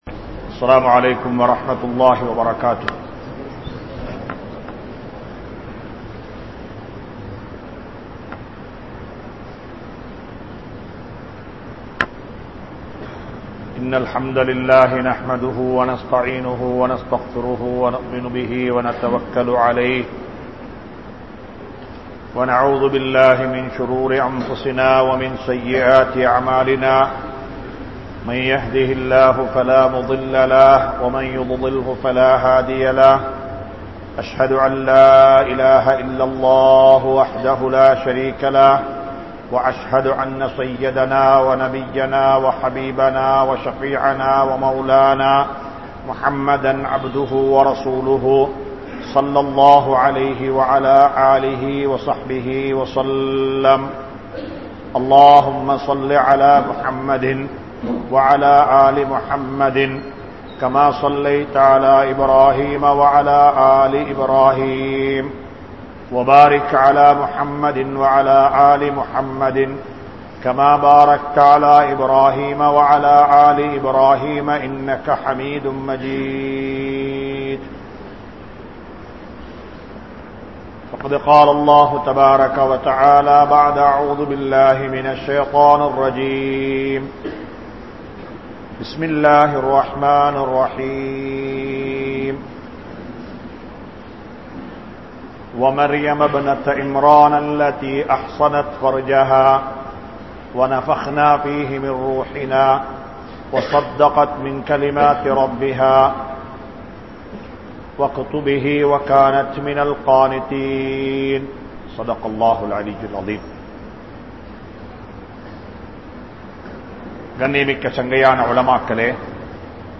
Nabi(SAW)Avarhalin Kudumbam (நபி(ஸல்)அவர்களின் குடும்பம்) | Audio Bayans | All Ceylon Muslim Youth Community | Addalaichenai